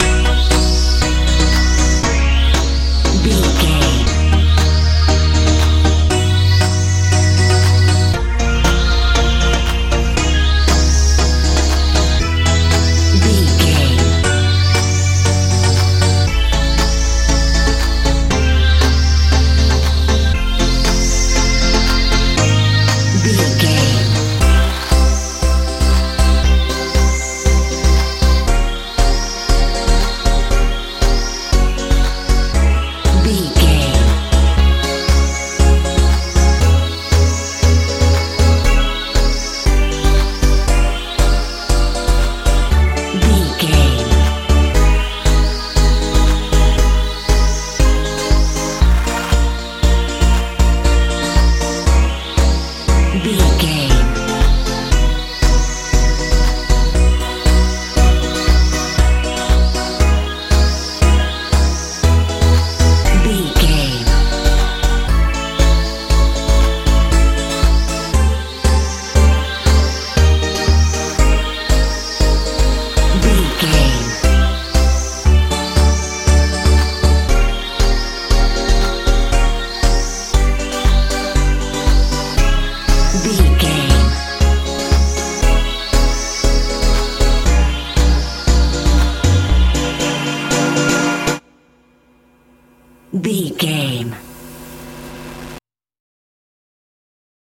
euro dance fee
Ionian/Major
E♭
magical
mystical
bass guitar
drums
synthesiser
80s
90s
playful